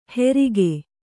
♪ herige